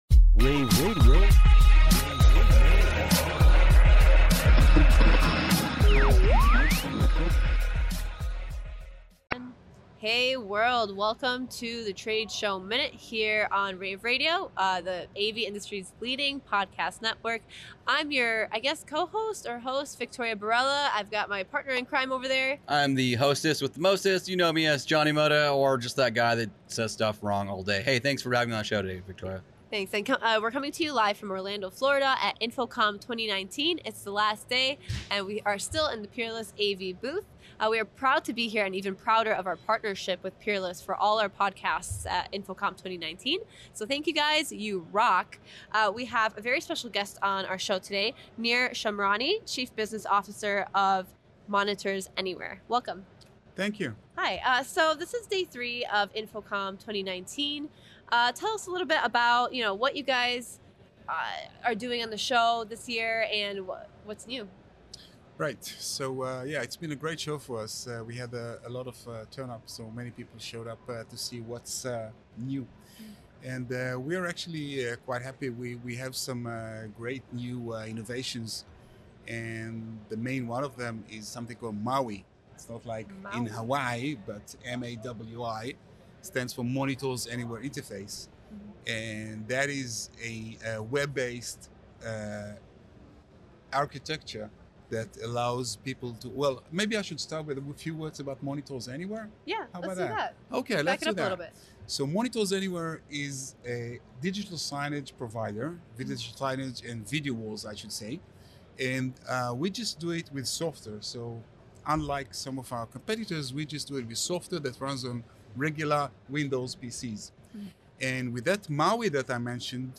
June 14, 2019 - InfoComm, InfoComm Radio, Radio, rAVe [PUBS], The Trade Show Minute,